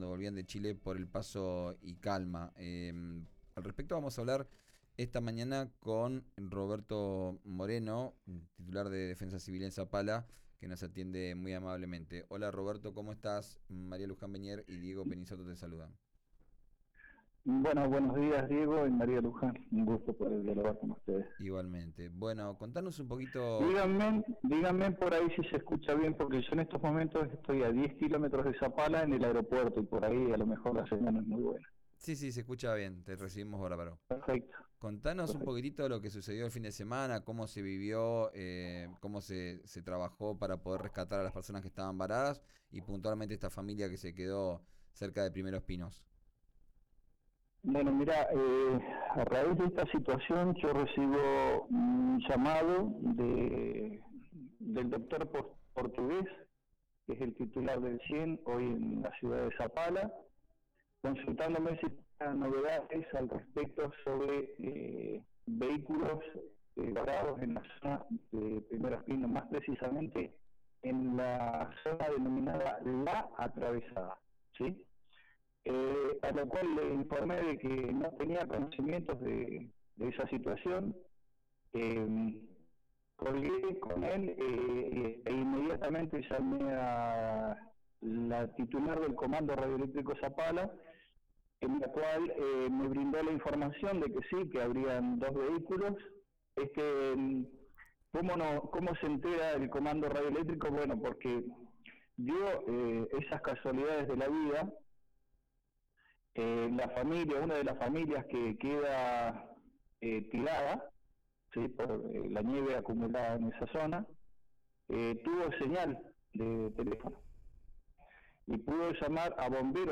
Escuchá al director de Defensa Civil de Zapala en RÍO NEGRO RADIO